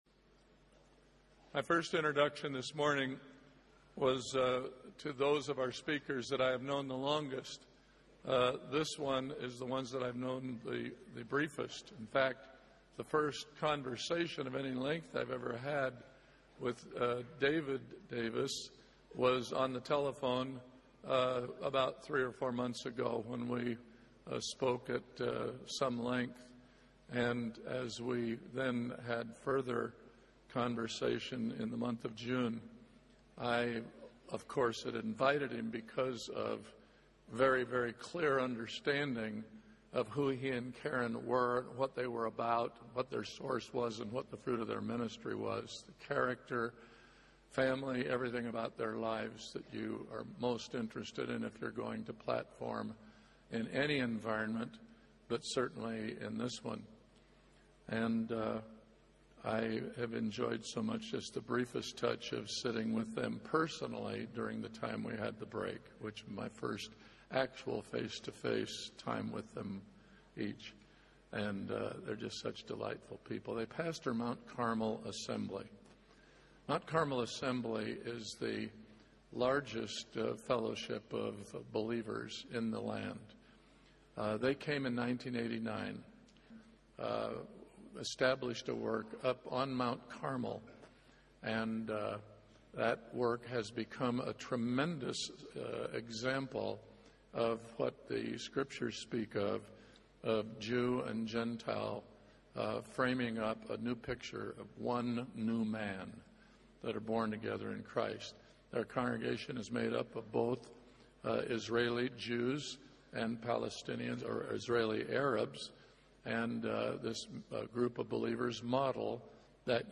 In this sermon, the speaker emphasizes the increasing number of Jews and Arabs getting saved, comparing it to the early days of the church in the Book of Acts. He urges the audience to wake up spiritually and see the need to share the gospel with the Jewish people. The speaker also mentions the importance of picking up books from different speakers at the conference to deepen their understanding and faith. The sermon concludes with a personal testimony of the speaker's encounter with Jesus and the transformative power of His forgiveness.